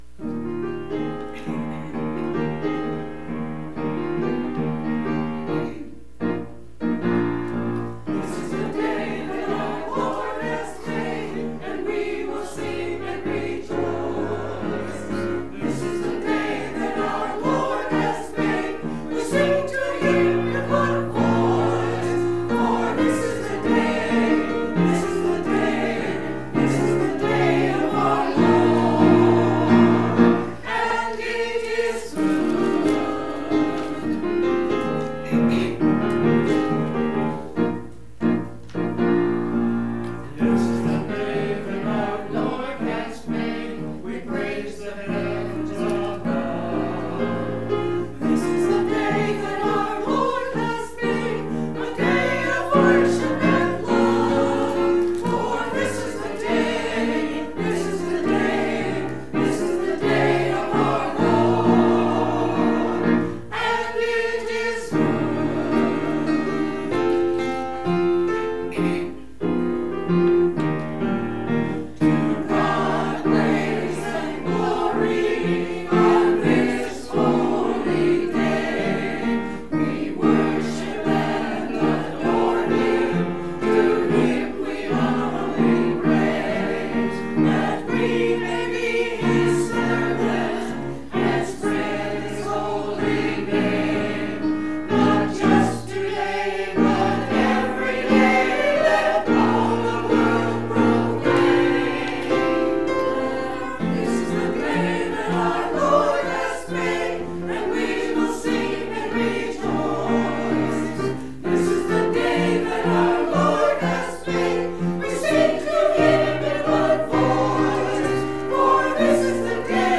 Church Choir – Anthem for the Lord’s Day 10.28.18
To hear the church choir praise God with Amazing Grace please click play below.